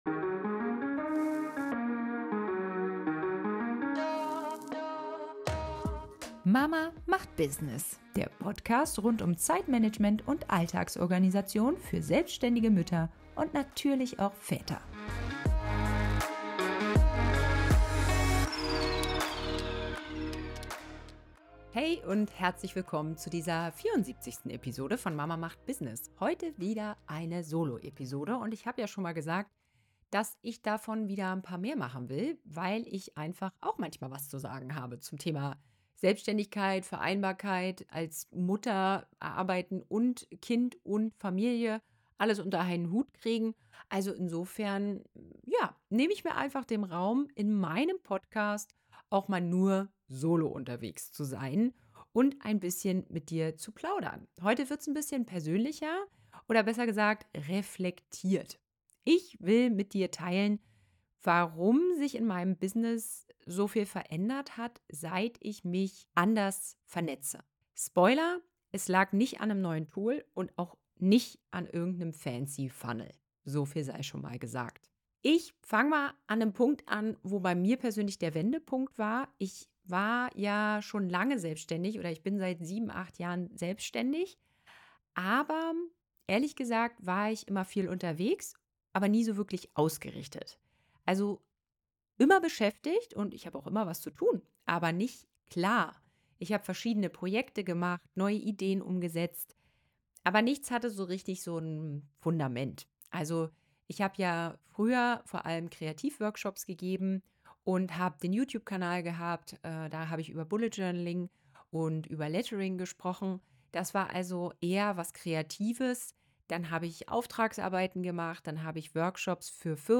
#74 Von der Einzelkämpferin zur Unternehmerin: durch echtes Netzwerken | Solofolge ~ Business Brunchies Podcast